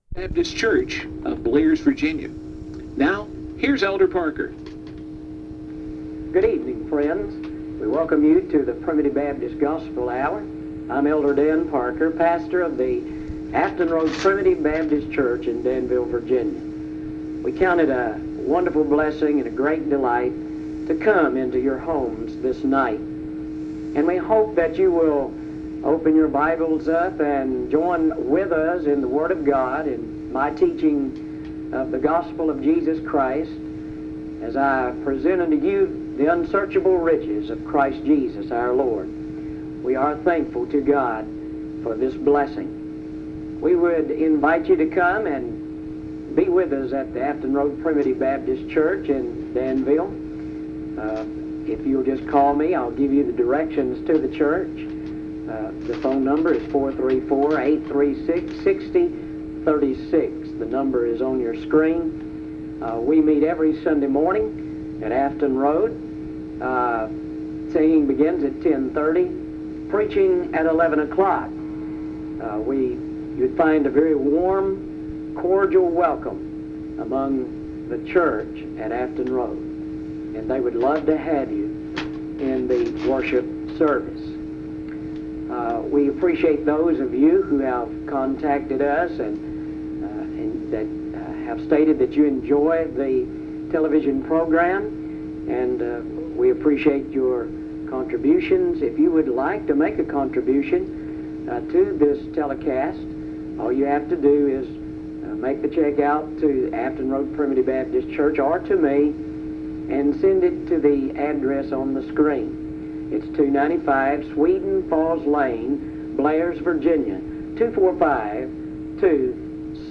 Mark 16:14-20; Matthew 28:18-20; Recording from a broadcast